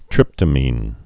(trĭptə-mēn)